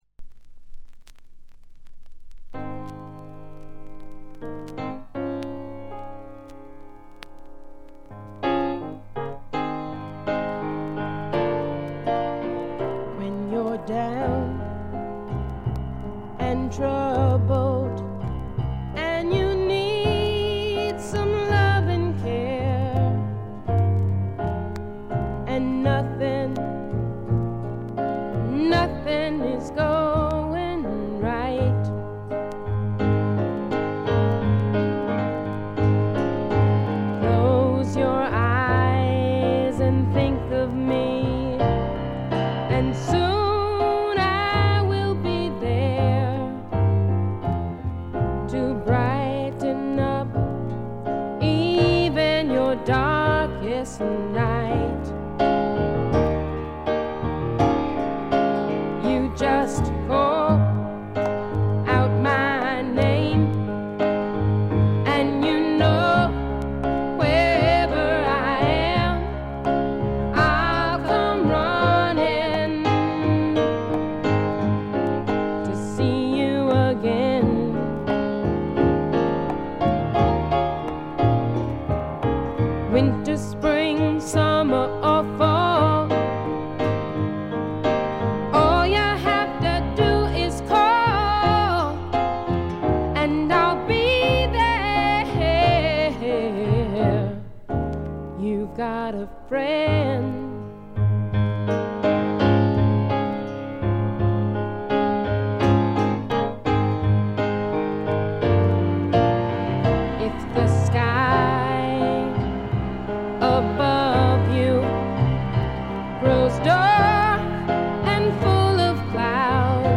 A1序盤冒頭チリプチ、B1冒頭チリプチ少し。
他は微細なチリプチ程度。
女性シンガーソングライター基本中の基本。
試聴曲は現品からの取り込み音源です。